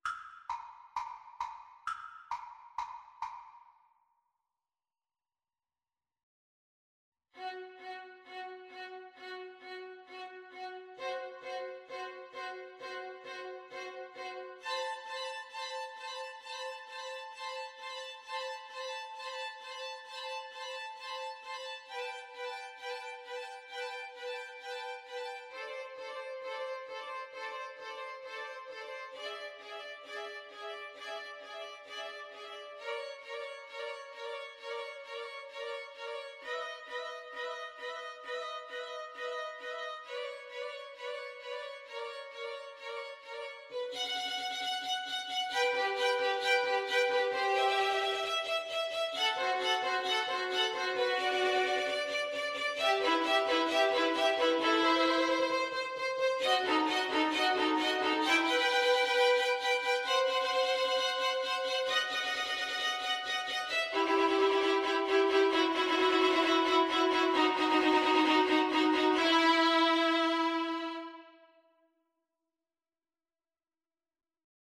Allegro non molto =c.132 (View more music marked Allegro)
Violin Trio  (View more Easy Violin Trio Music)
Classical (View more Classical Violin Trio Music)